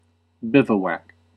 Ääntäminen
IPA : /ˈbɪv.u.æk/